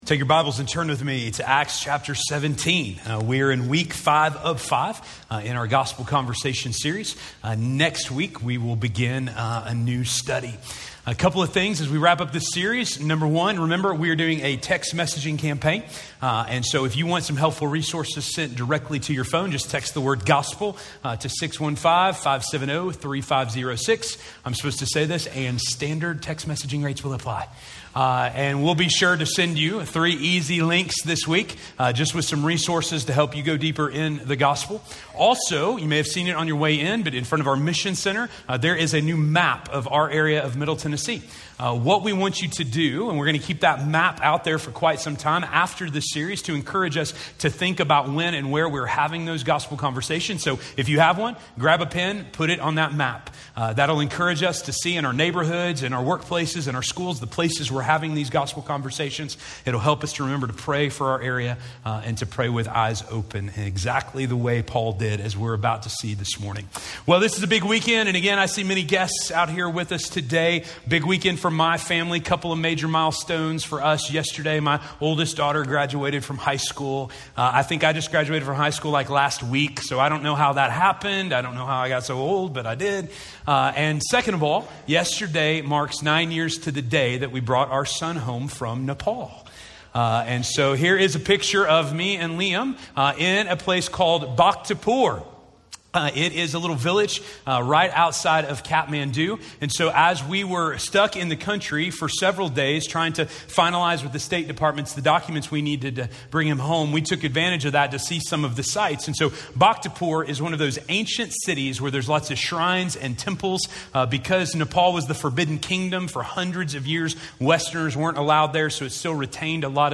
That They Might Seek God - Sermon - Station Hill